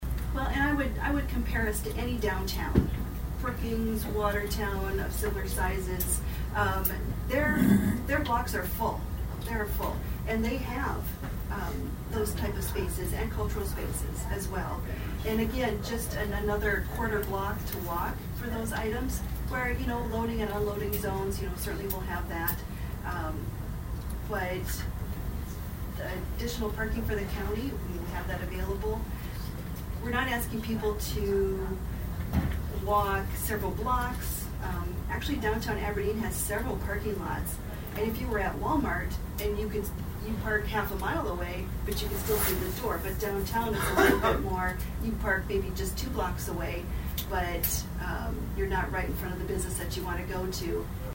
ABERDEEN, S.D.(HubCityRadio)- At Tuesday’s Brown County Commission meeting, the commissioners address a resolution dealing with the possible expansion of Dacotah Prairie Museum.